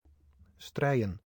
Strijen (Dutch pronunciation: [ˈstrɛiə(n)]